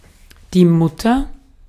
Ääntäminen
Synonyymit noodle bonce (slangi) bollock ball loony nutbag nutcase nutter nads loaf live one Ääntäminen UK : IPA : /nʌt/ US : IPA : /nʌt/ UK : IPA : [nɐt] Haettu sana löytyi näillä lähdekielillä: englanti Käännös Konteksti Ääninäyte Substantiivit 1.